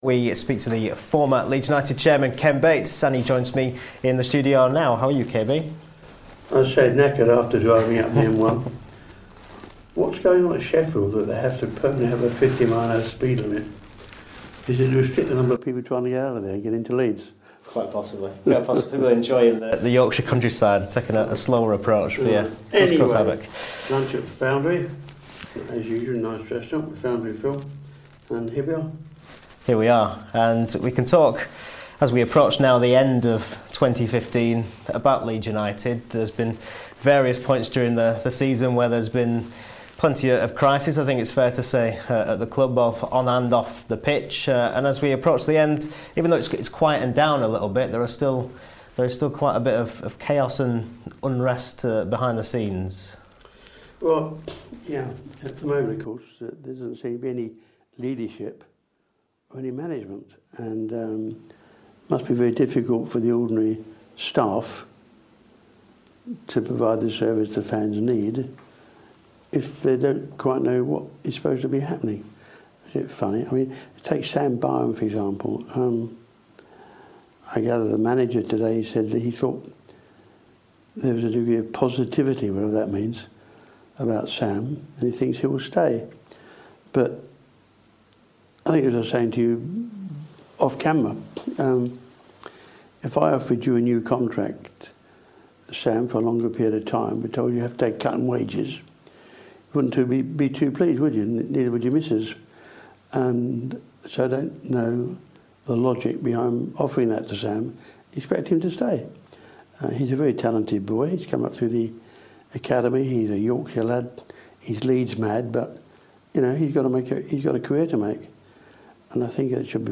Former Chelsea and Leeds United chairman Ken Bates has been speaking to Radio Yorkshire discussing Leeds 2015, Sam Byram's contract situation and the continued plight of David Haigh.